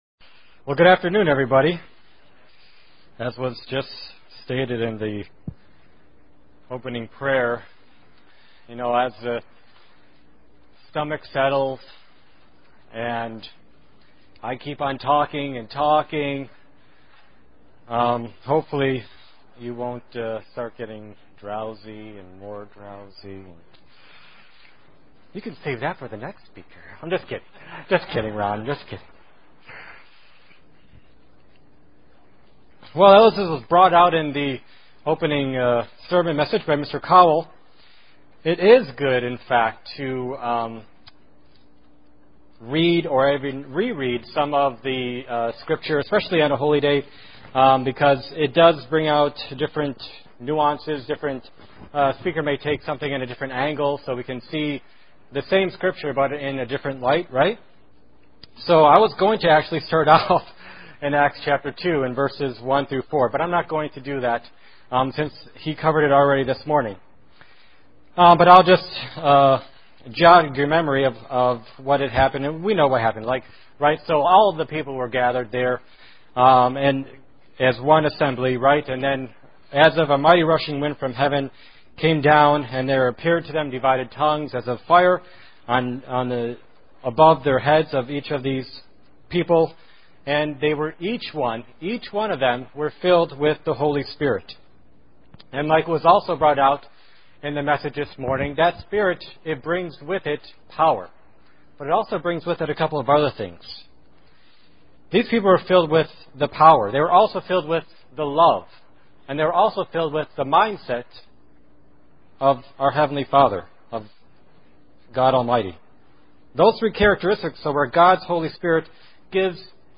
UCG Sermon Studying the bible?
Given in Elmira, NY